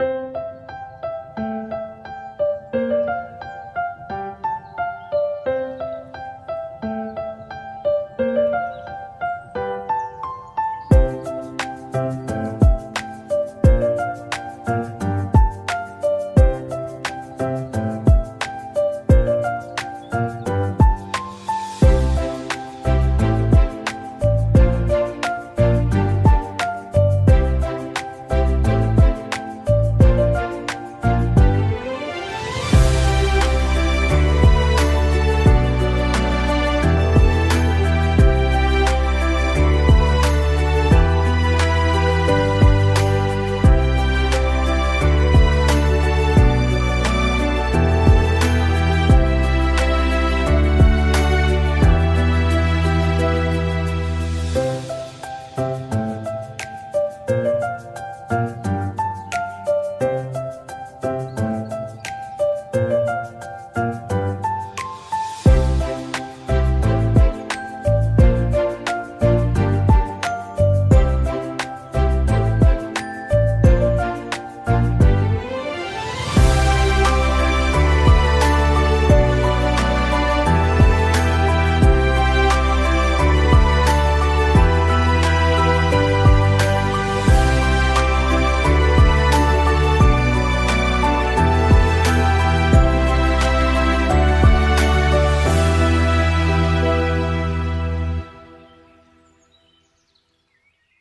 BGM
Marika's Theme (Serene Take 4).mp3